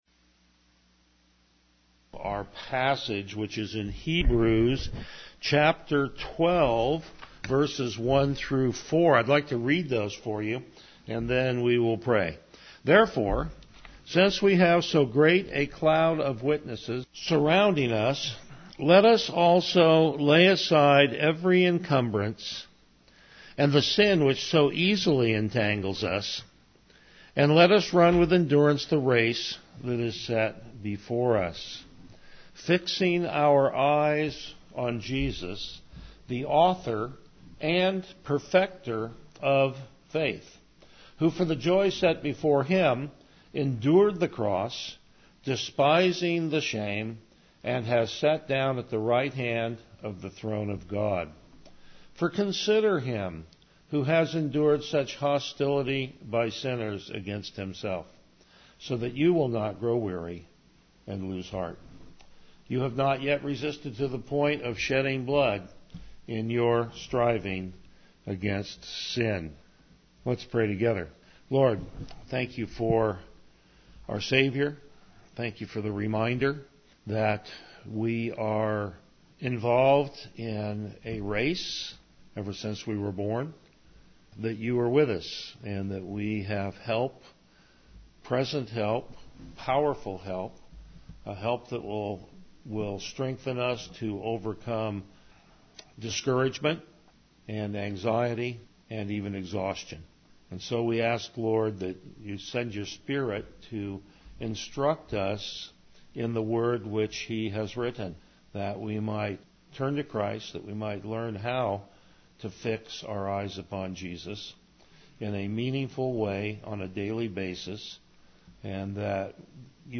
Passage: Hebrews 12:2-4 Service Type: Morning Worship
Verse By Verse Exposition